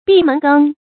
注音：ㄅㄧˋ ㄇㄣˊ ㄍㄥ
閉門羹的讀法